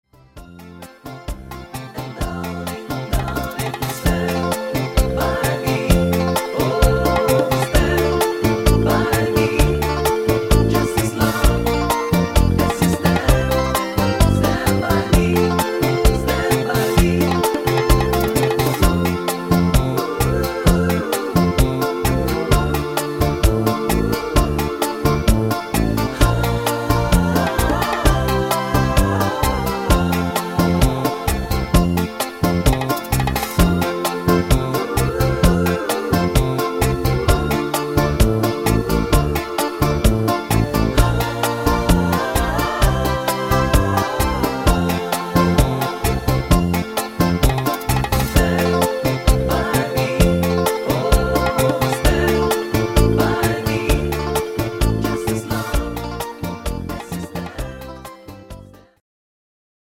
Bachata Version